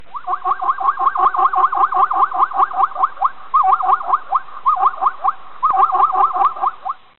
Звуки зебры
Общение зебры в период размножения